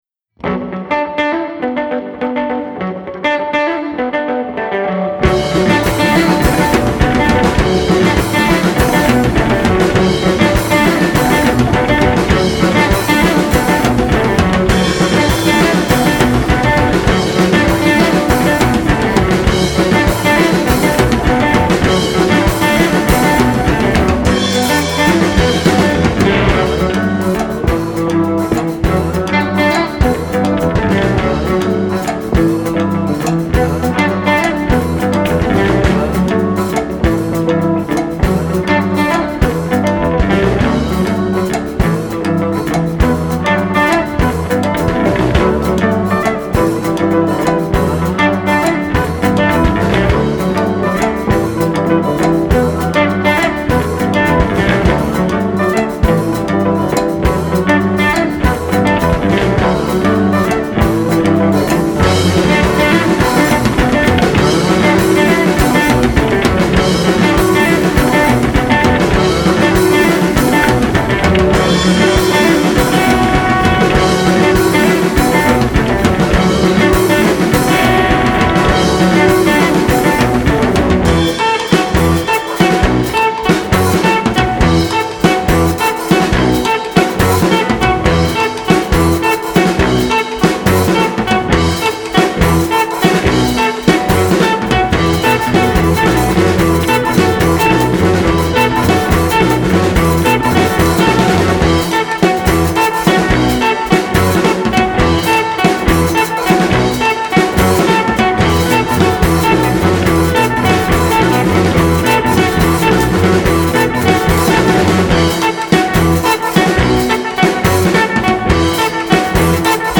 trio rock instrumental